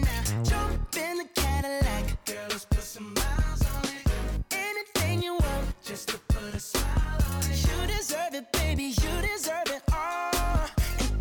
Допустим, это (околопопс современный)
вот ослабил мид, так может лучше слышно.